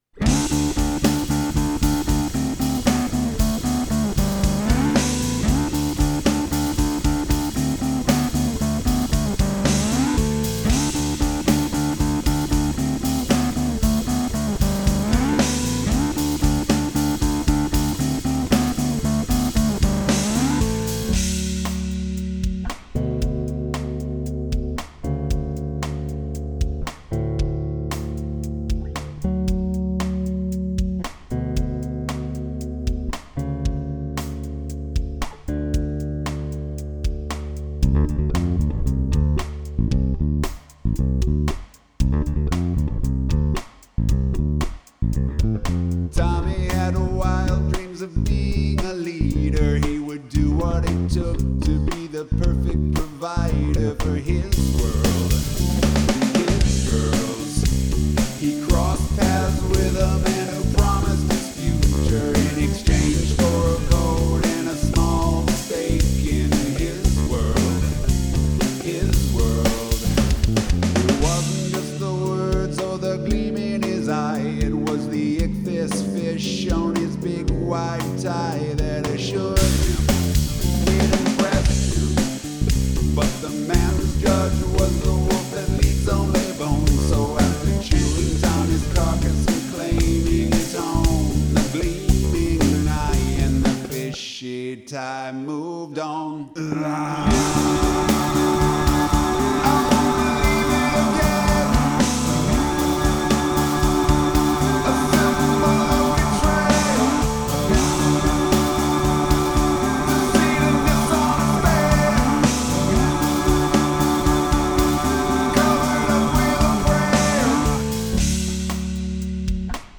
..This is a tune off of an 'all-bass' project im messing with that is bass guitar, drums, and vocals only.